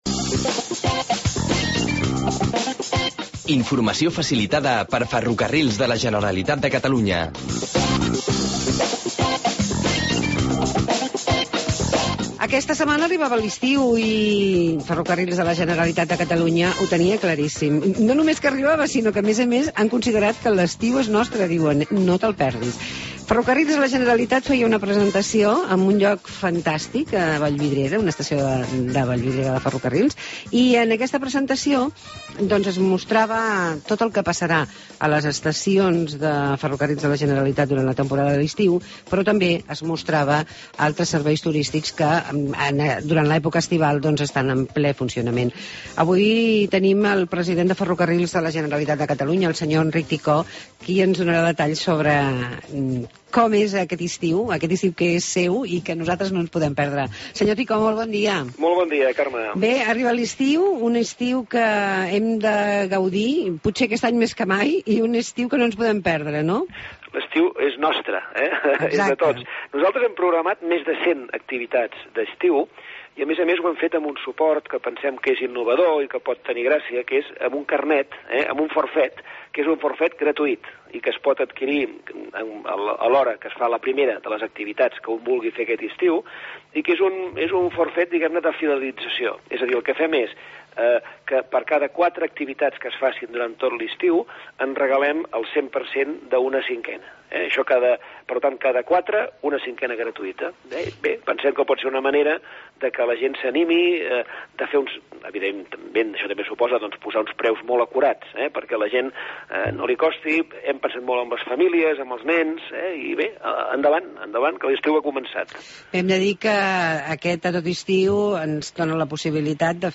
Entrevista amb Enric Ticó, president de Ferrocarrils de la Generalitat de Catalunya